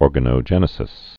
(ôrgə-nō-jĕnĭ-sĭs, ôr-gănə-)